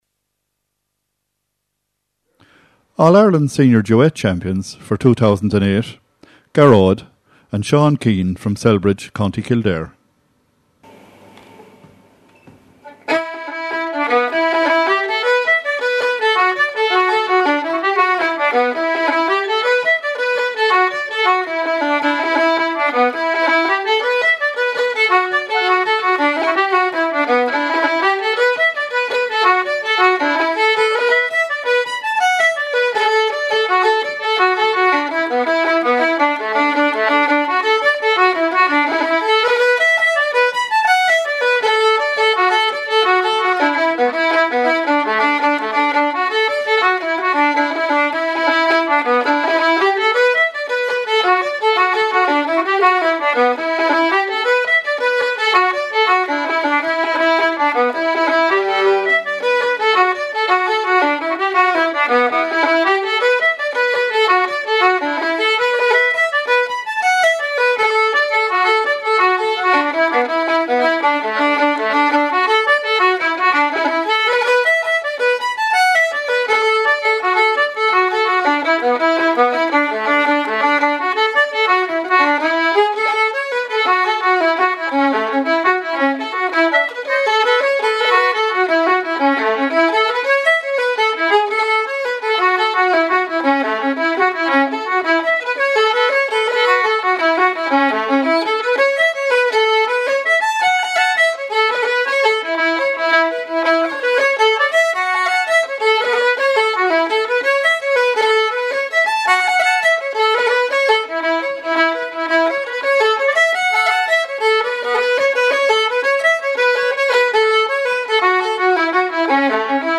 2008 All-Ireland Under-18 Trio champions play a set of reels: “The Belles of Tipperary” and “St. Ruths’ Bush”.
fiddle
piano accordion
flute